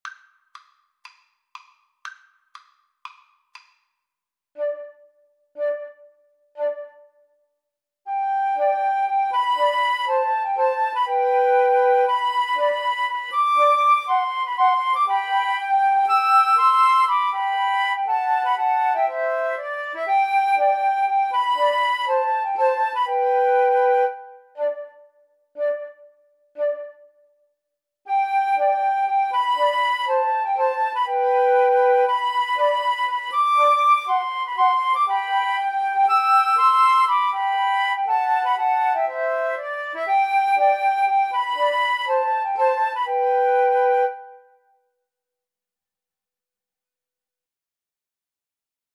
G major (Sounding Pitch) (View more G major Music for Flute Trio )
Moderately Fast
Traditional (View more Traditional Flute Trio Music)